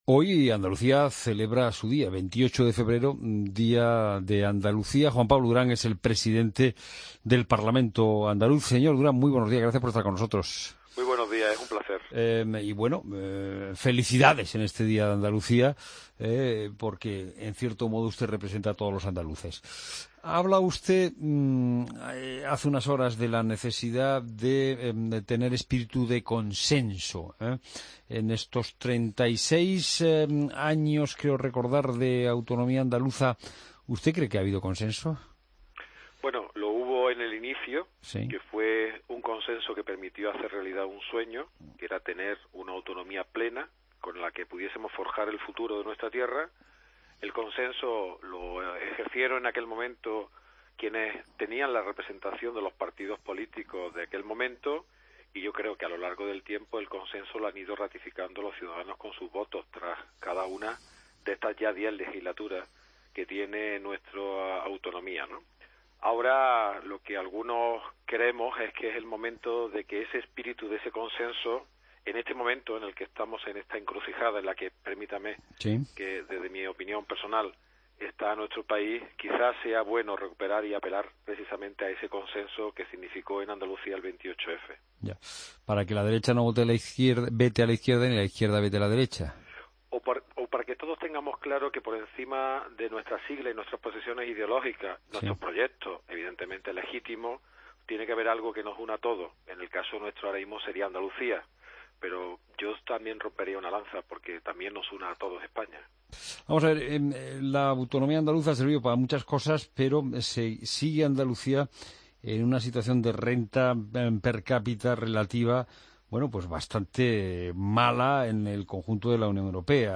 Escucha la entrevista a Juan Pablo Durán Sánchez, Presidente del Parlamento Andaluz, en La Mañana Fin de Semana de COPE